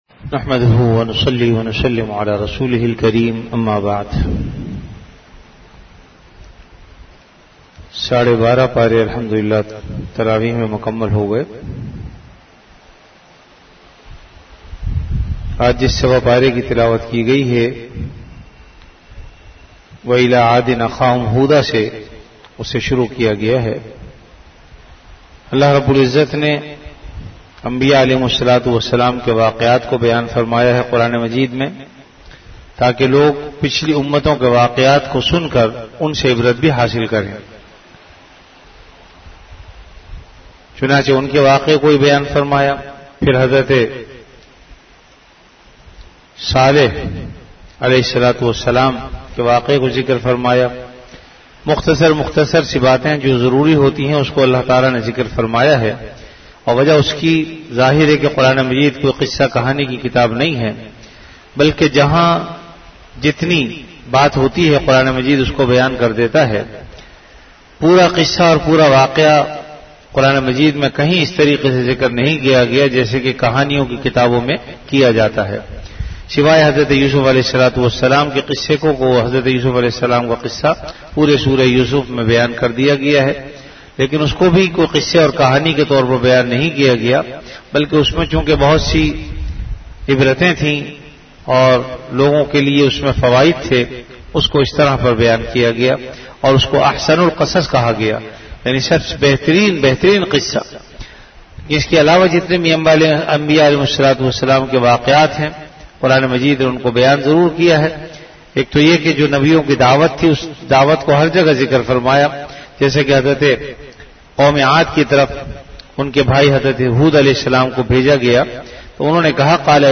Delivered at Jamia Masjid Bait-ul-Mukkaram, Karachi.
Ramadan - Taraweeh Bayan · Jamia Masjid Bait-ul-Mukkaram, Karachi